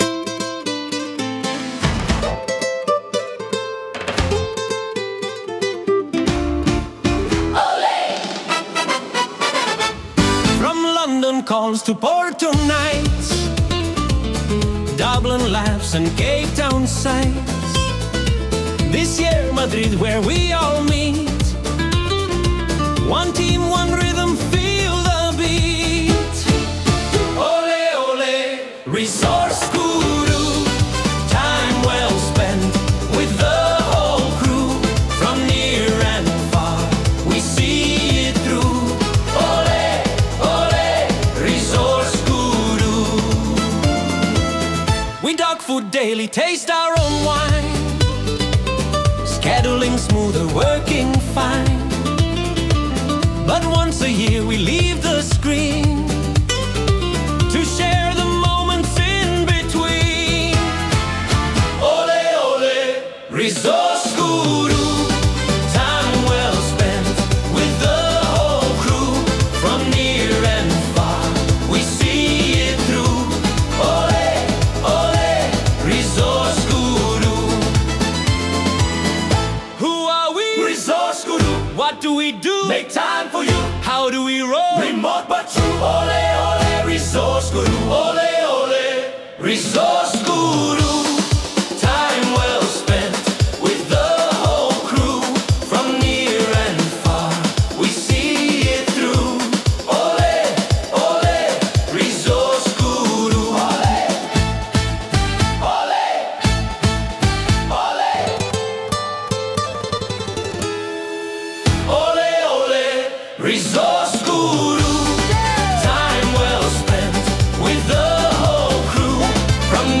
For our final dinner in Madrid, we headed to a local cookery school to try our hand at making some Spanish classics.
Often in the form of live musicians that seem to appear out of thin air.
This year, we were serenaded by a guitarist who not only provided the perfect ambiance for our self-made meals, he also learned a Guru-ific theme song.